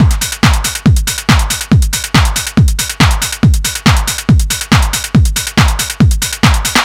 NRG 4 On The Floor 017.wav